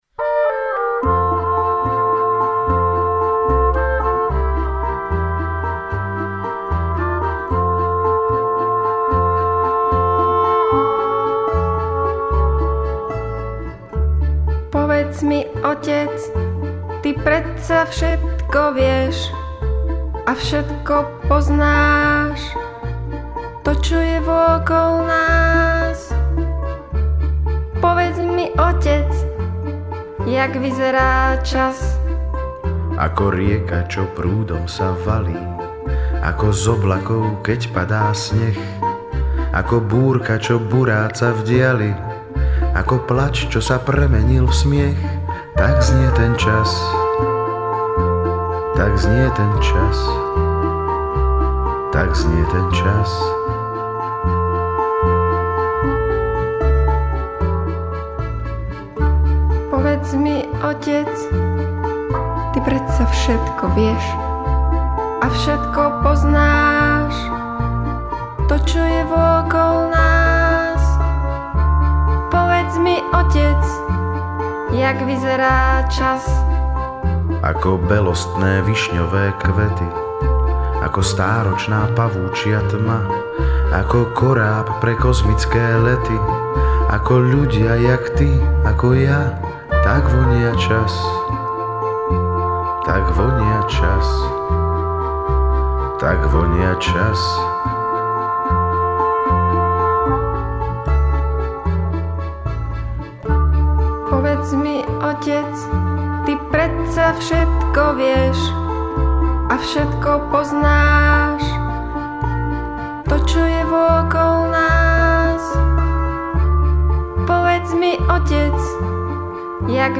kontrabas